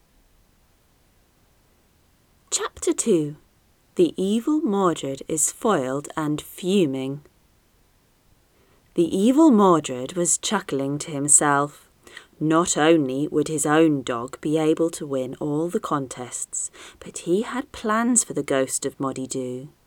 Those loud esses are one of the reasons it fails the ACX test.
Your microphone system is insanely harsh and crisp.
The basic voice sounds perfect if we can solve this.
The clip is a bit noisy (FFFFFFFFF), so drag-select some of the two second Room Tone (silence) at the beginning > Effect > Noise Reduction > Profile.